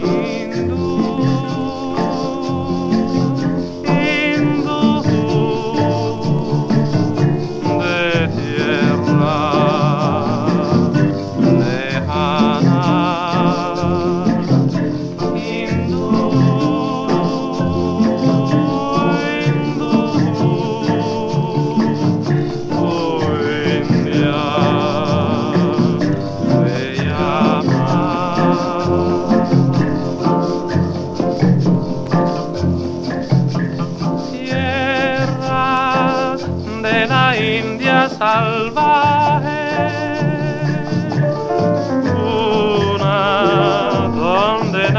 World, Latin, Rumba, Cubano　Germany　12inchレコード　33rpm　Mono